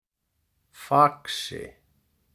The Faxi (Icelandic pronunciation: [ˈfaksɪ]
Faxi_pronunciation.ogg.mp3